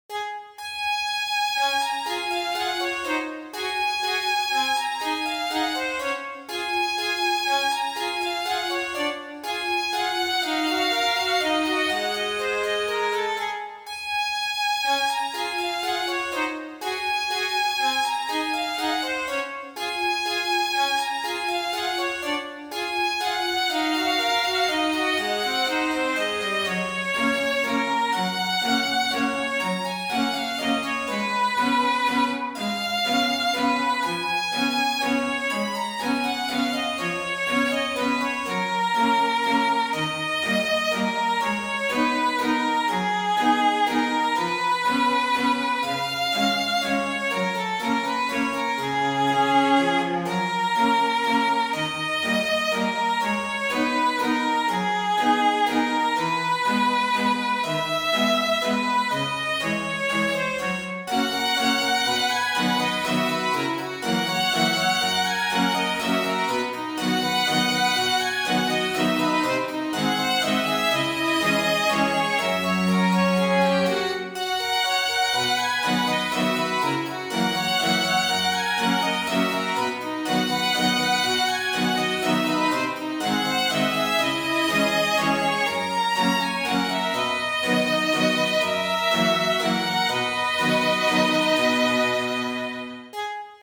ogg(L) 優雅 しっとり バイオリン
みんなの憧れ。バイオリンが気品良く響き渡る。